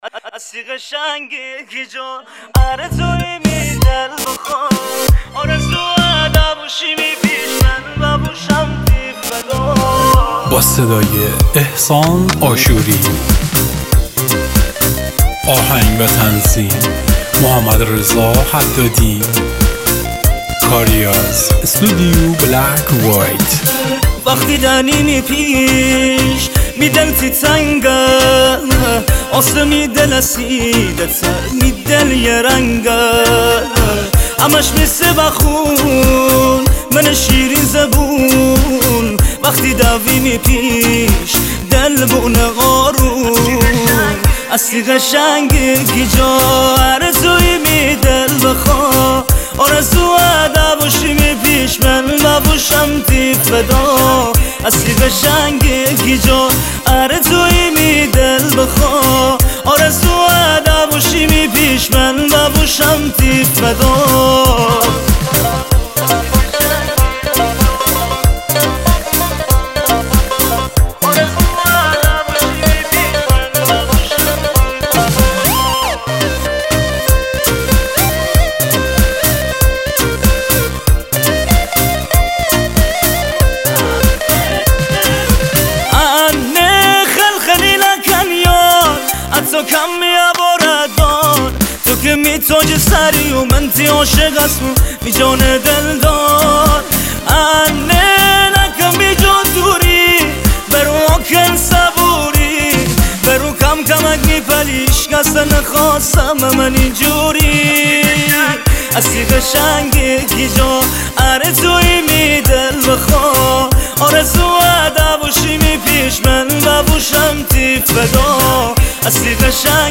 مازندرانی